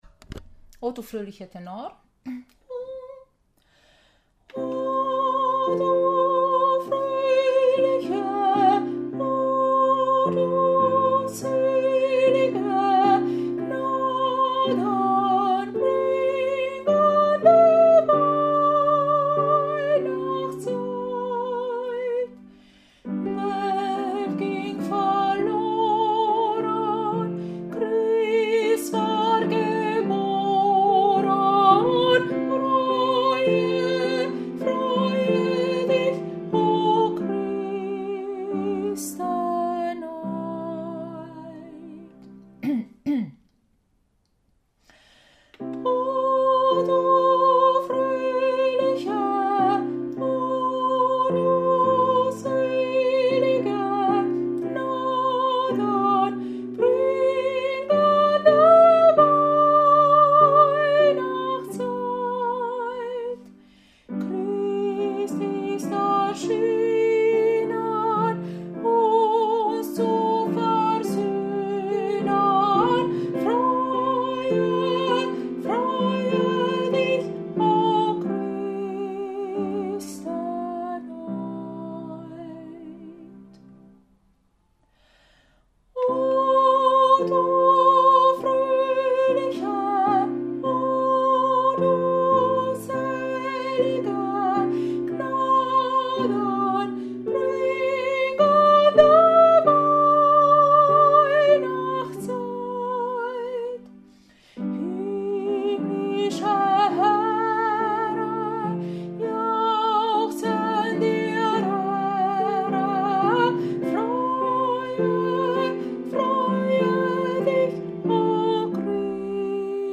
Oh du Fröhliche Tenor
Oh-Du-Fröhliche-Tenor.mp3